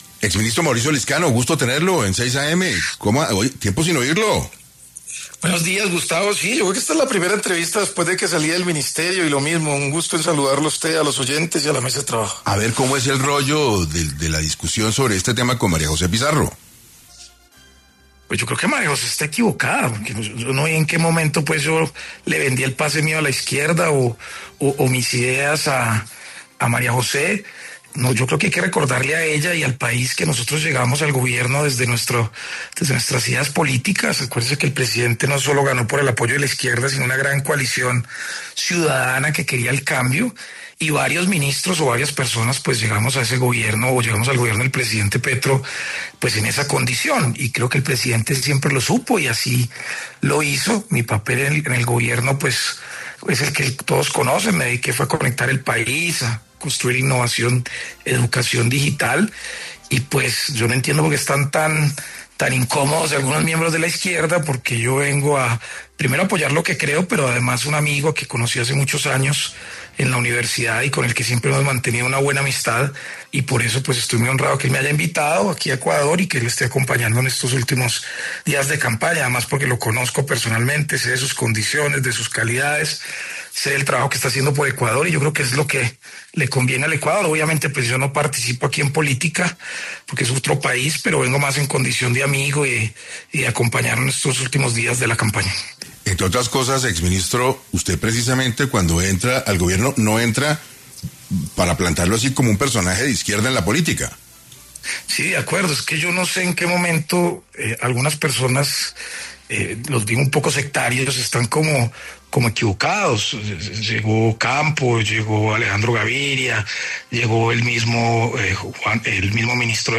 En Caracol Radio estuvieron Mauricio Lizcano, exministro de las TIC, y la congresista María José Pizarro hablando sobre la visita del exministro al presidente de Ecuador.